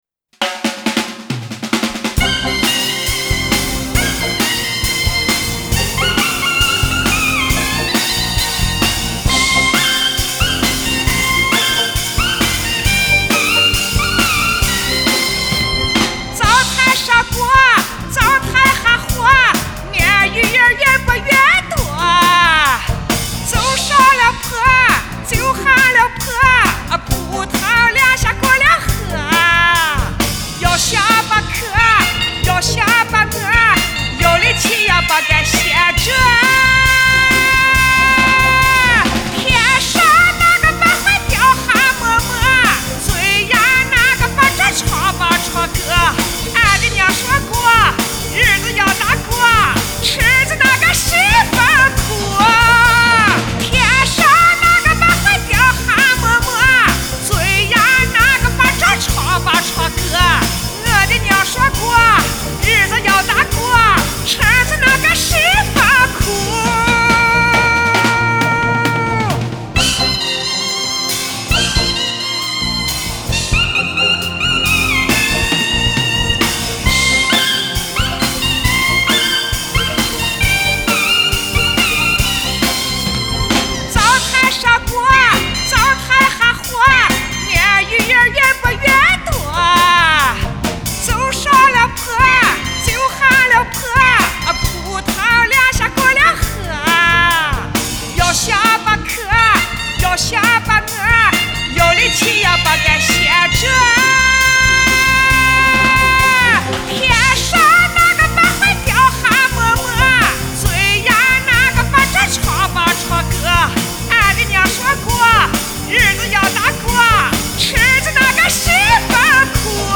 声音很特别
乡土味特浓，真喜欢这张专辑！
原汁原味的西部民歌，喜欢。